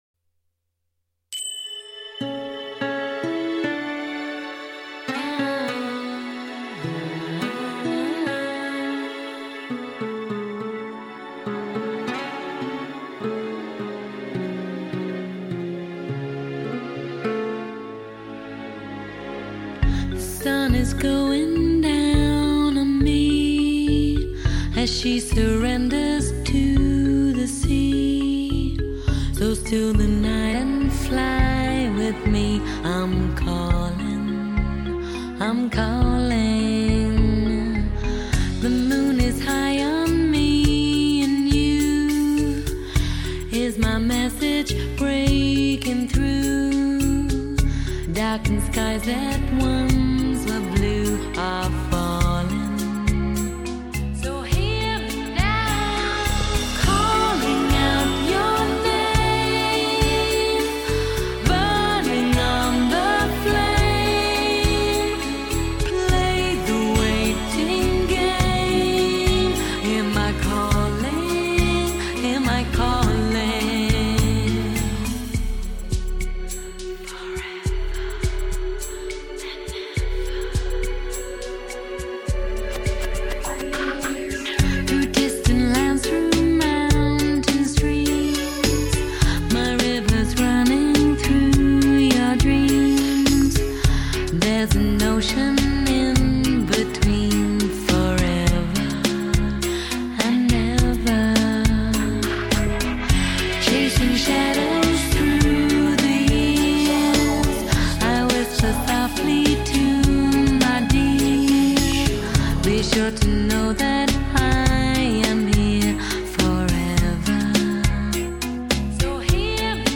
Румба танцуется великолепно!